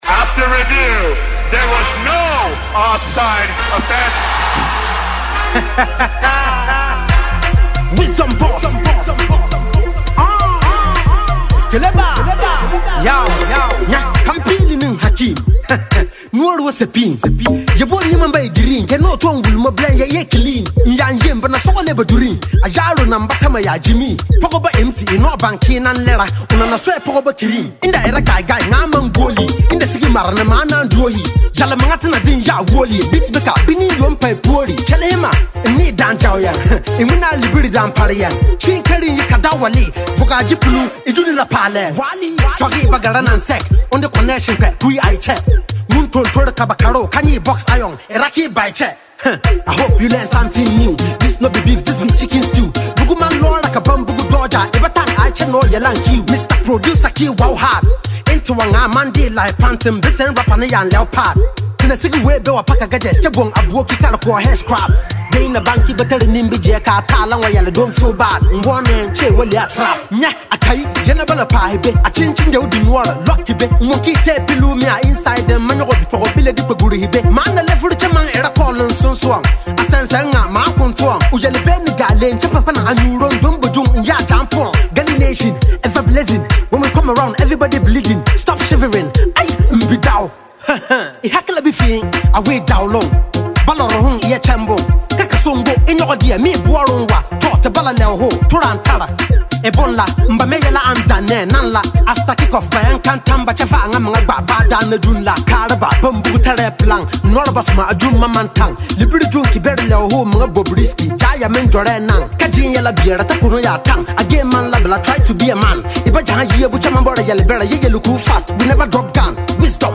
a highly talented Ghanaian Afropop, Rapper